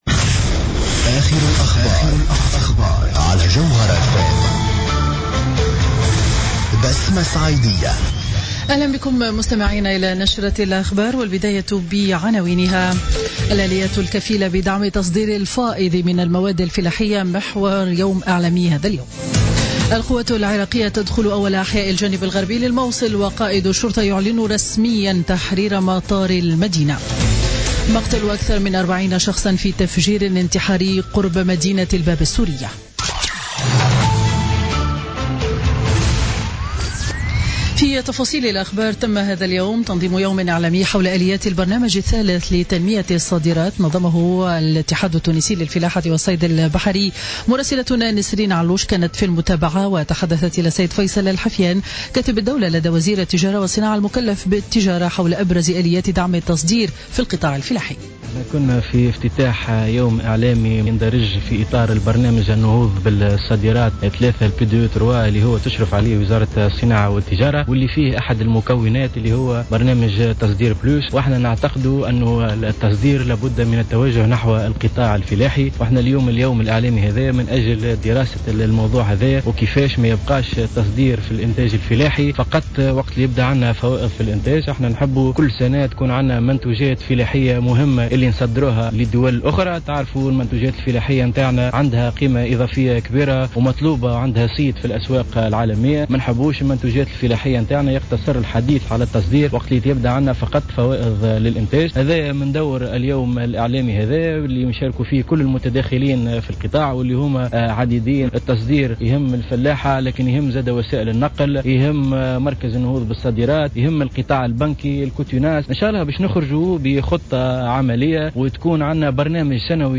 نشرة أخبار منتصف النهار ليوم الجمعة 24 فيفري 2017